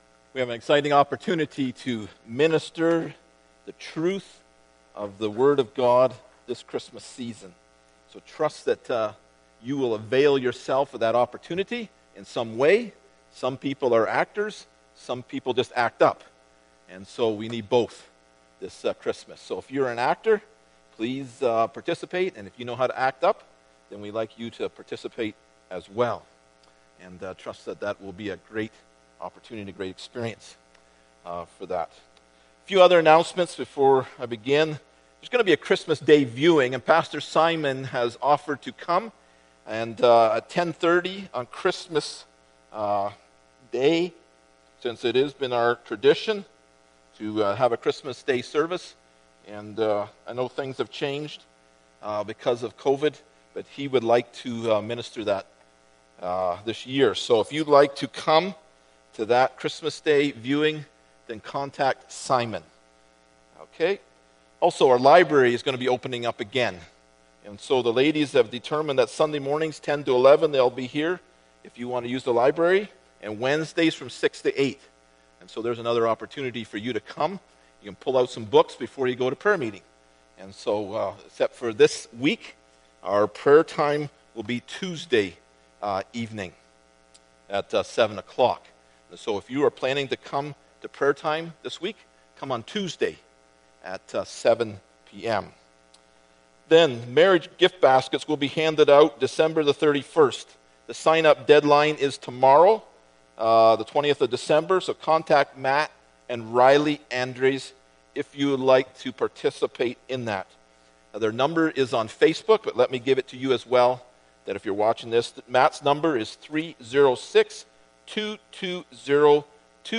1 John 4:7-12 Service Type: Sunday Morning Bible Text